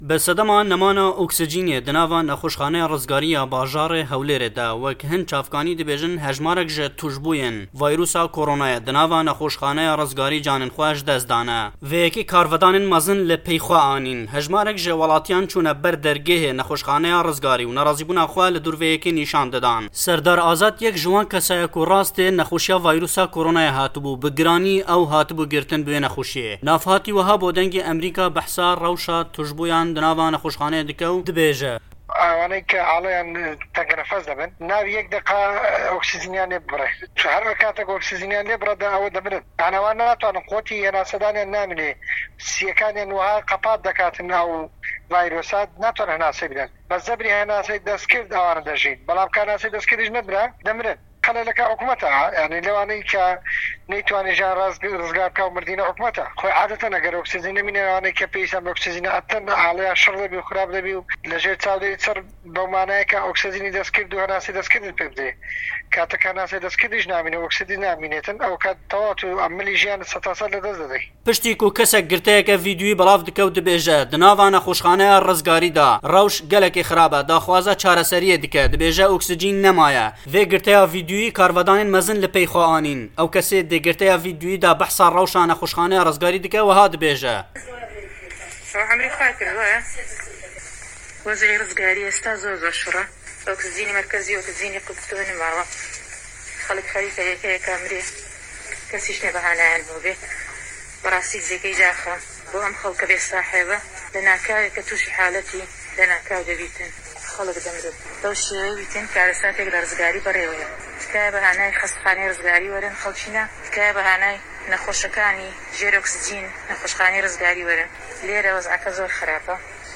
درێژەی ڕاپـۆرتەکە لەم فایلە دەنگیـیەدایە: